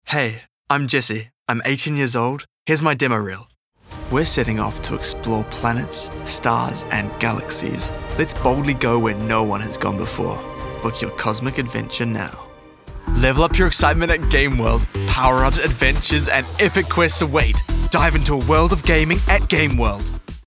Demo
Teenager, Young Adult
new zealand | natural
standard us | natural
NARRATION 😎
sincere
warm/friendly